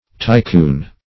tycoon - definition of tycoon - synonyms, pronunciation, spelling from Free Dictionary
Tycoon \Ty*coon"\ (t[-i]`k[=oo]n"), n. [Chinese tai-kun great